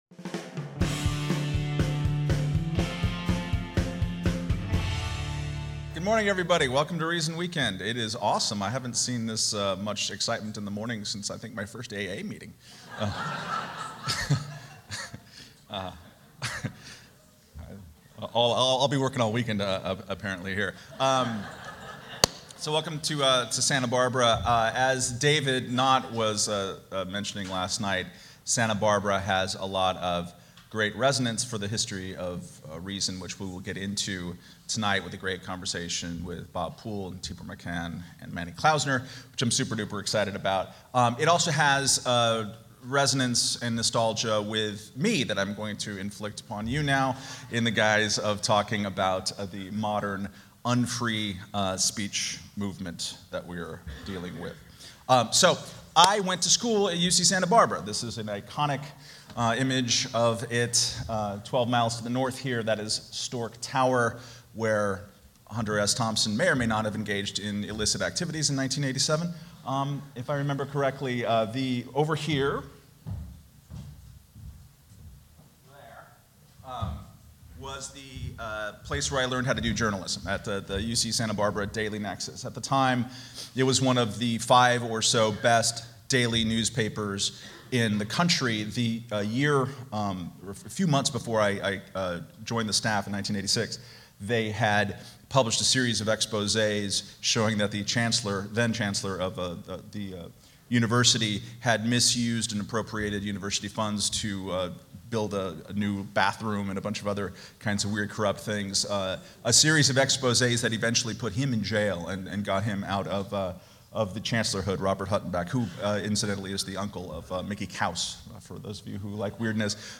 At Reason Weekend 2015, the annual donor event for the nonprofit that publishes this website